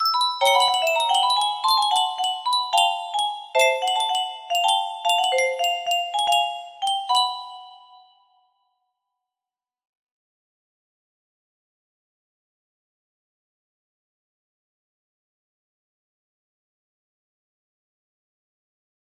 Песня music box melody